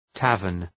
Προφορά
{‘tævərn}